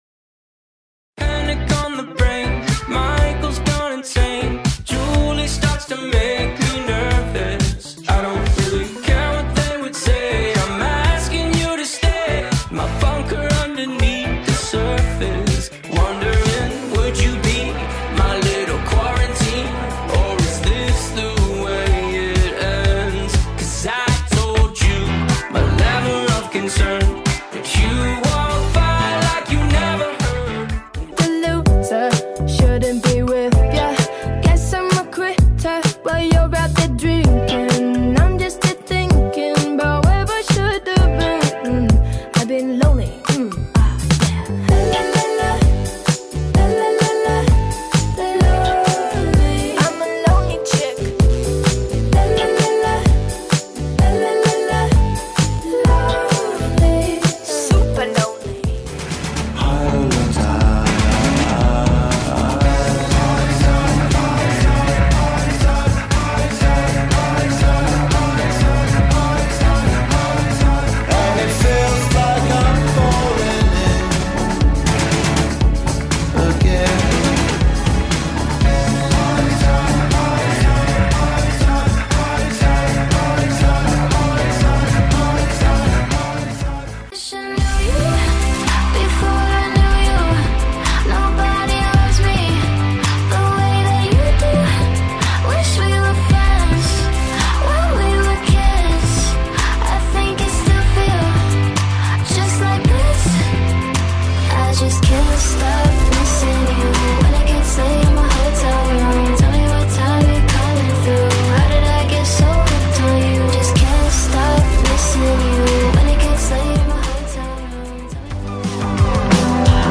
Alternative Indie Hits from Popular Artists